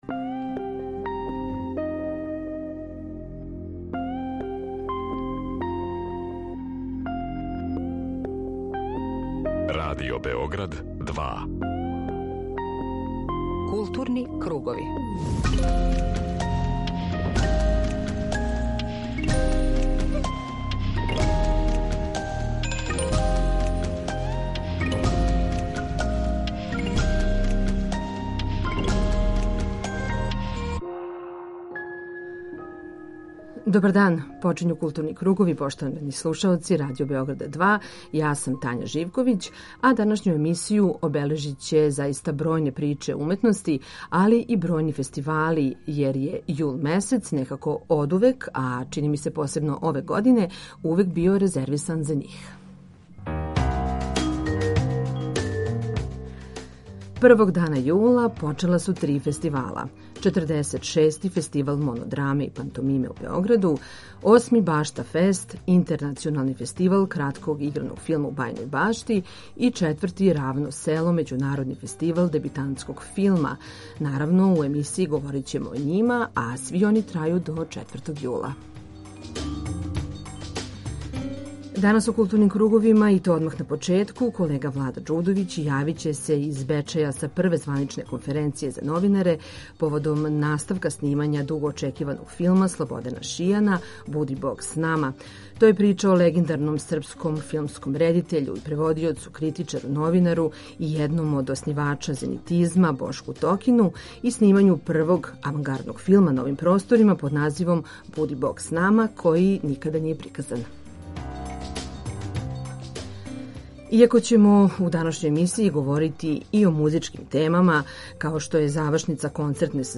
Група аутора Централна културно-уметничка емисија Радио Београда 2.